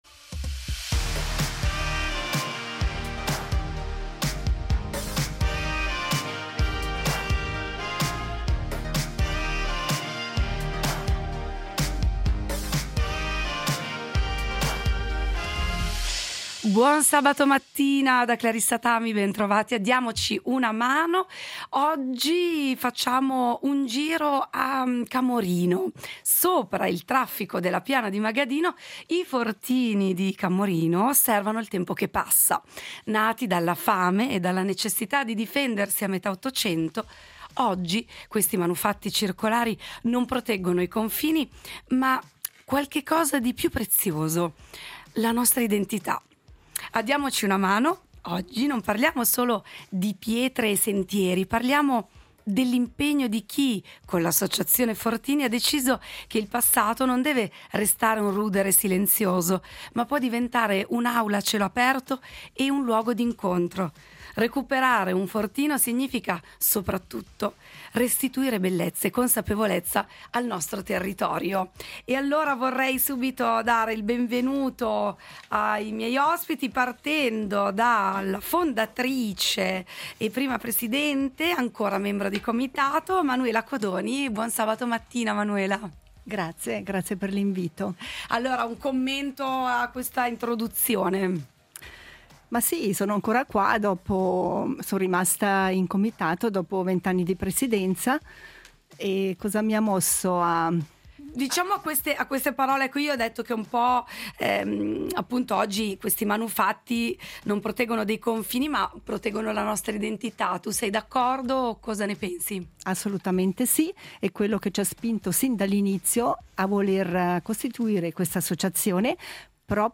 In studio le voci dell’ Associazione Fortini di Camorino .